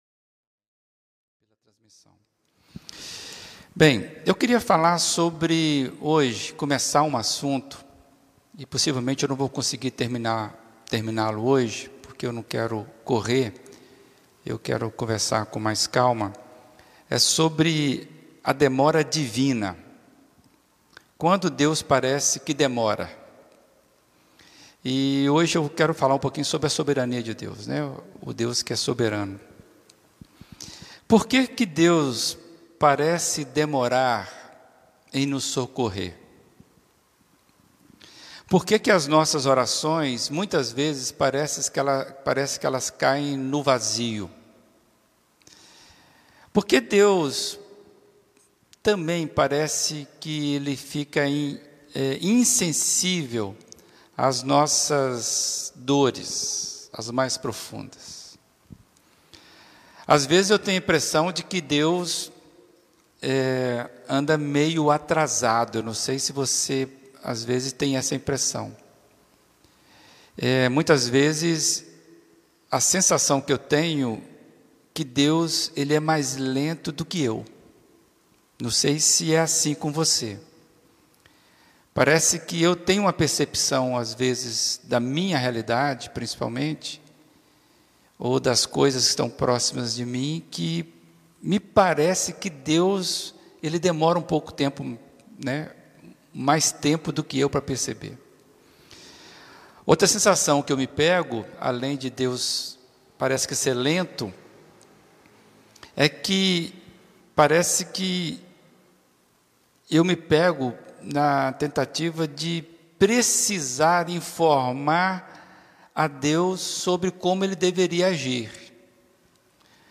Mensagem
na Primeira Igreja Batista de Brusque